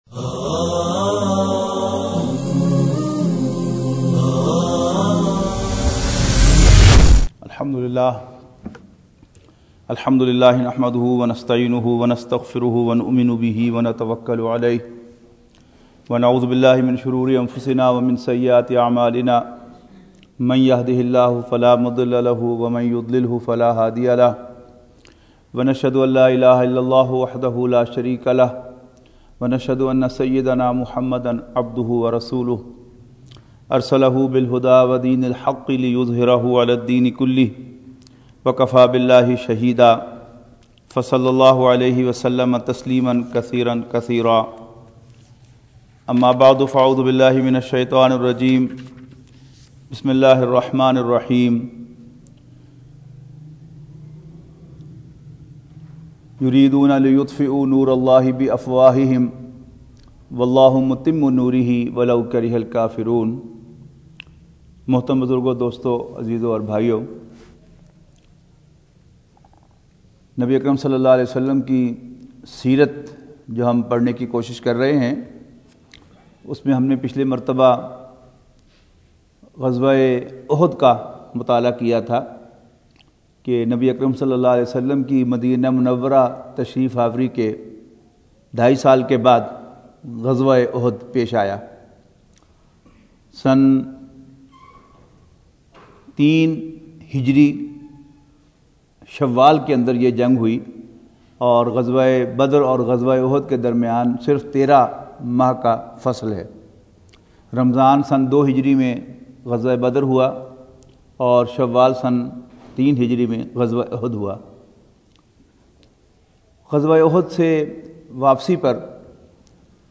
Khitab E Juma / Audio / Imran Khan Or Seert Un Nabi 5